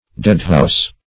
Search Result for " deadhouse" : The Collaborative International Dictionary of English v.0.48: Deadhouse \Dead"house`\, n. A morgue; a place for the temporary reception and exposure of dead bodies.